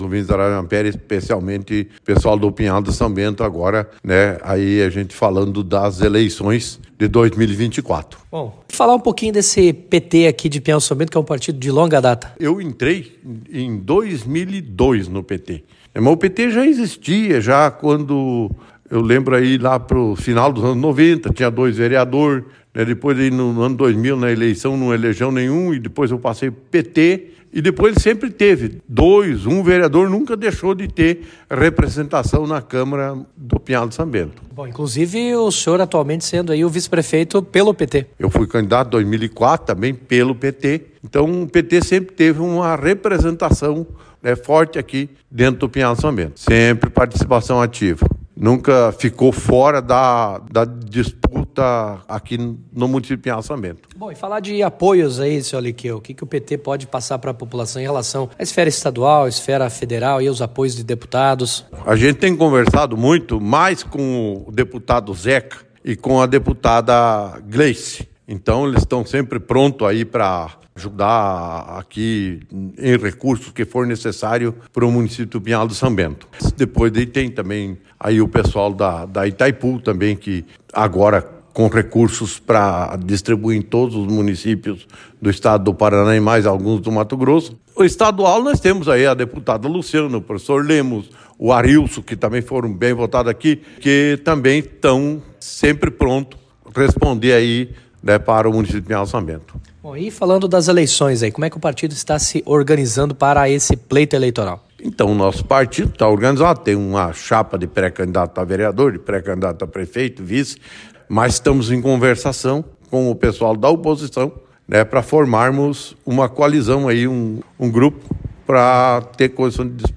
O vice-prefeito de Pinhal de São Bento, Aliqueu Savoldi, presidente do PT, destacou que o Partido dos Trabalhadores estará junto com o grupo de oposição no município. Em entrevista ao Jornalismo Ampére AM/Interativa FM, ele destacou que a legenda tem nomes para indicar como vice-prefeito e tem pré-candidatos a vereador.